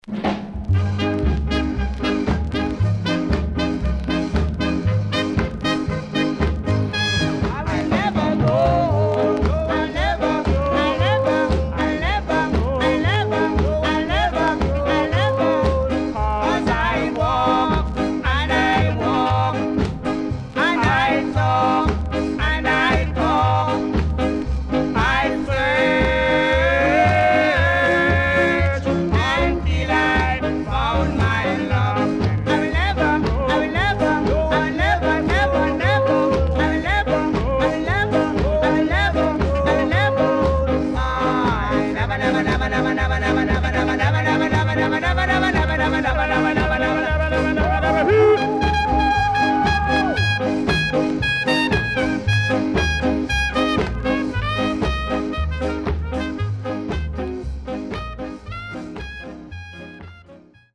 INST 60's
CONDITION SIDE A:VG〜VG(OK)
SIDE A:所々チリノイズがあり、少しプチノイズ入ります。前半途中で小傷により数発ノイズ入ります。